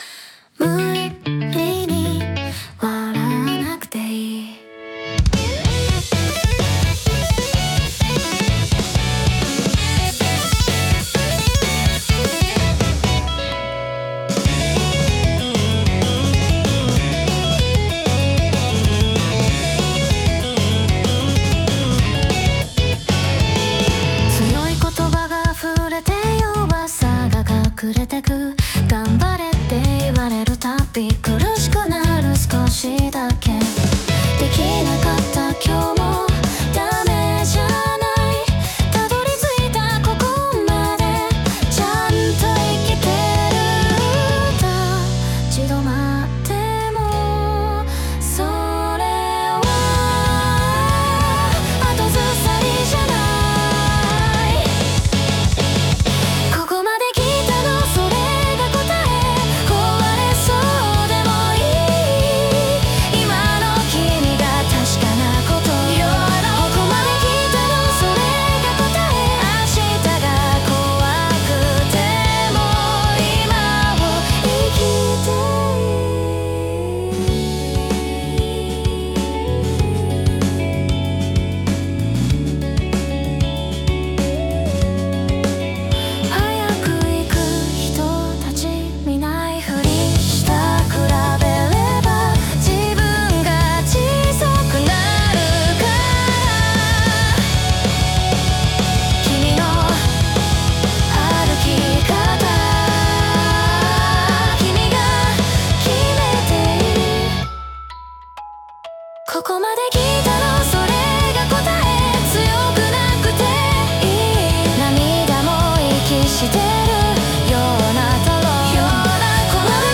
女性ボーカル
イメージ：J-POP,J-ROCK,女性ボーカル,かっこいい,優しい,切ない